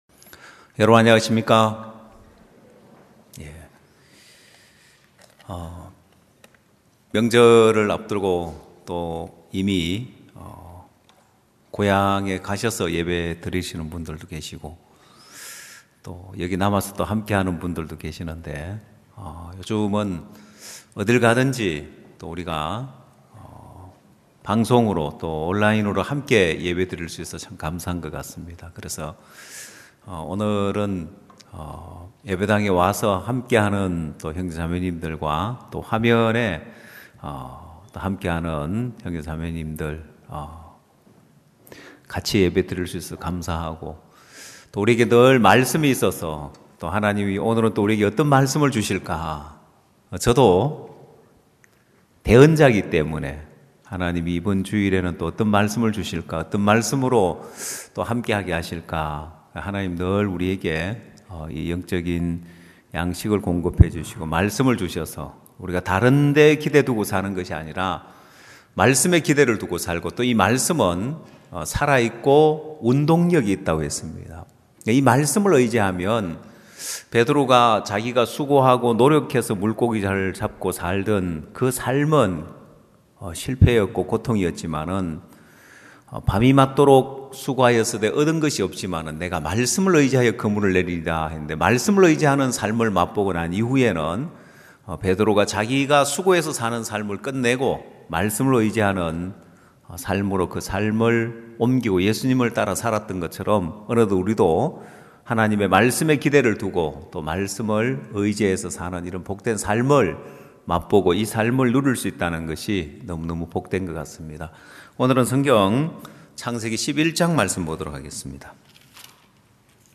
성도들이 모두 교회에 모여 말씀을 듣는 주일 예배의 설교는, 한 주간 우리 마음을 채웠던 생각을 내려두고 하나님의 말씀으로 가득 채우는 시간입니다.